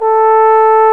Index of /90_sSampleCDs/Roland L-CDX-03 Disk 2/BRS_Trombone/BRS_Tenor Bone 2